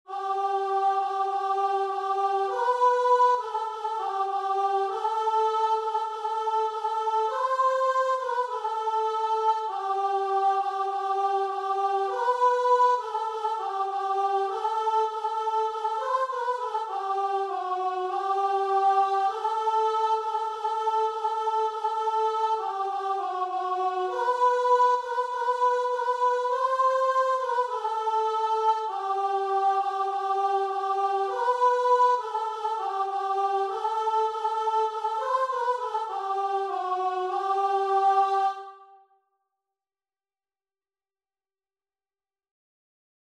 Christian
4/4 (View more 4/4 Music)
Guitar and Vocal  (View more Easy Guitar and Vocal Music)
Classical (View more Classical Guitar and Vocal Music)